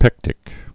(pĕktĭk)